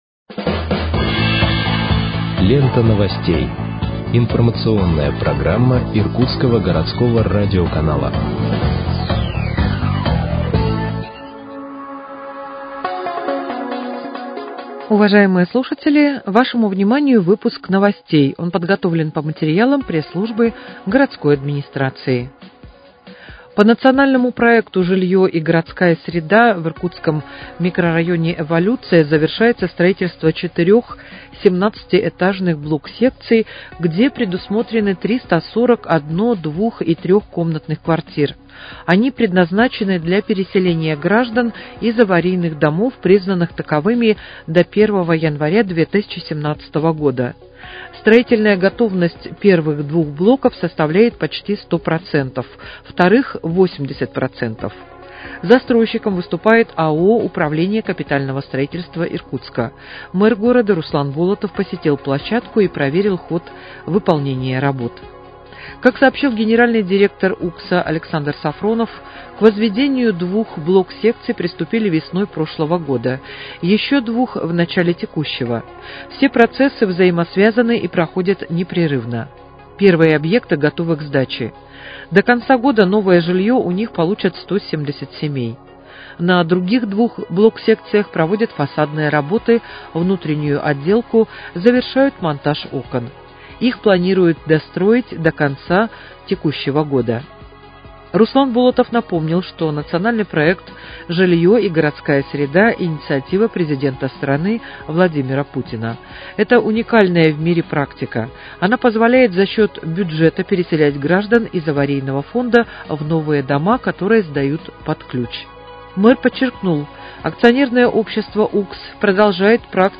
Выпуск новостей в подкастах газеты «Иркутск» от 26.09.2024 № 1